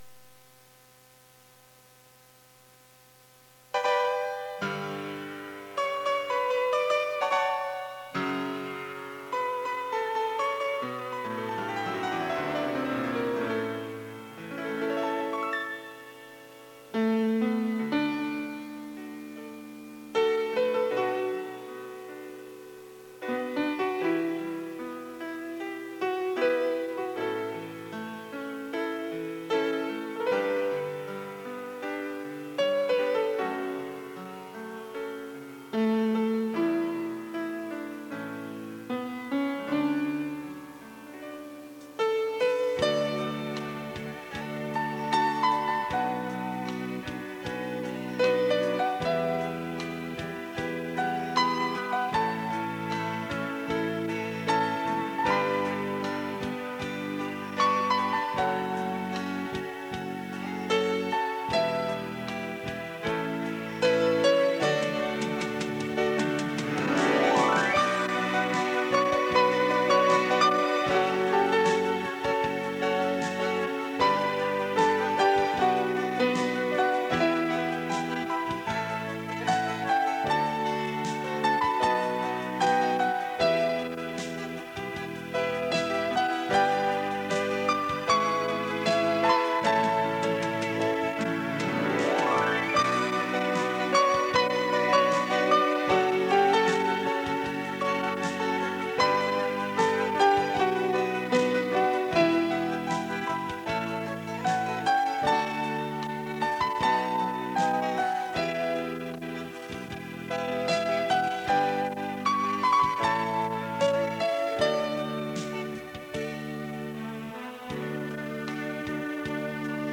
钢琴演奏专辑
磁带数字化：2022-05-30